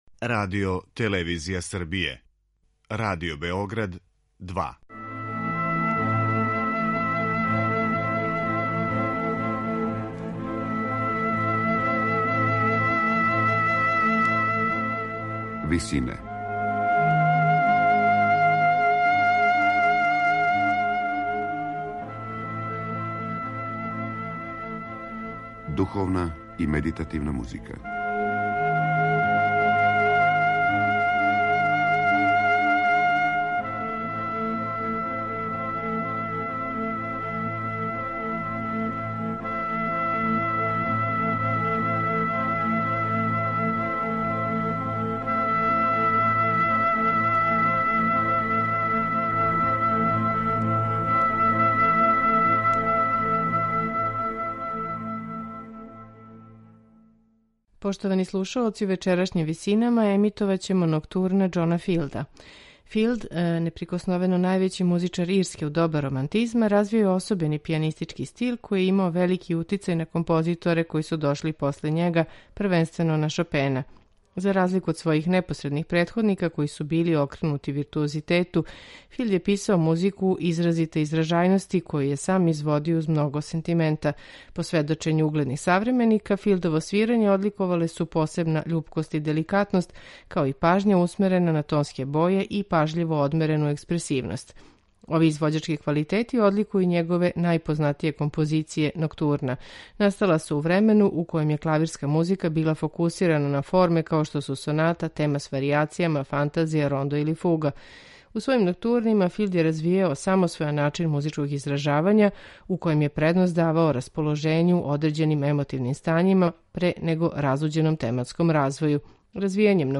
ноктурна
пијанисте